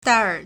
戴尔 (戴爾) dàiěr
dai4er3.mp3